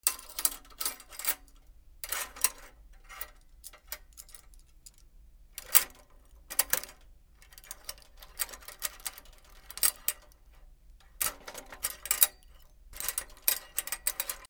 / M｜他分類 / L01 ｜小道具 / 金属
金属の器具を調整する ネジ等
『カシャカシャ』